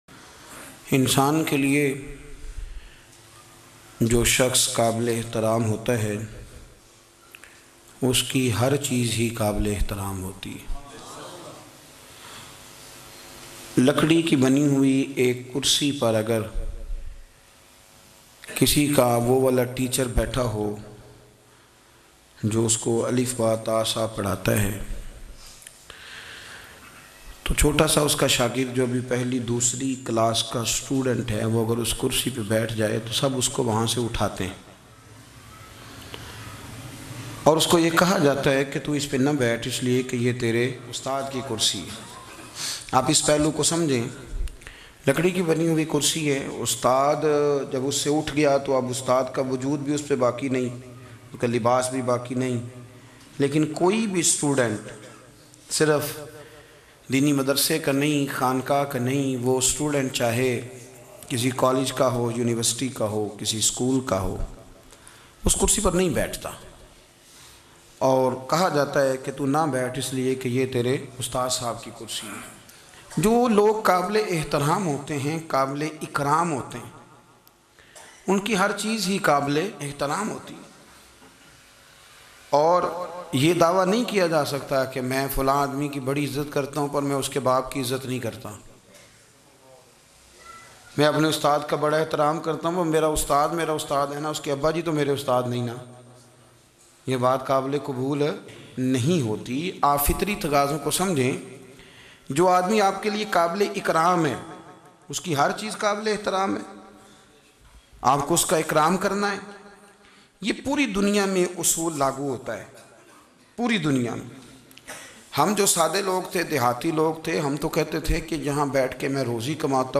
Hazoor Sy Nisbat Rakhny Wali Her Cheez Ka Adab Bayan MP3